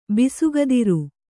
♪ bisugadiru